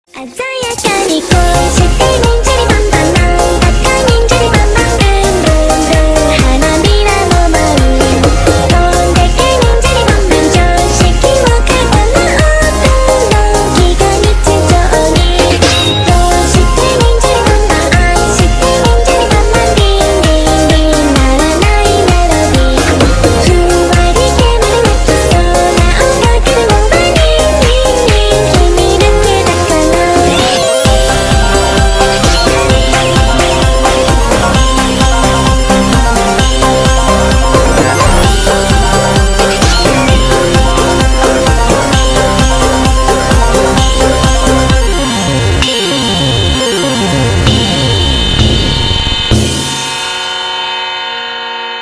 • Качество: 192, Stereo
поп
японские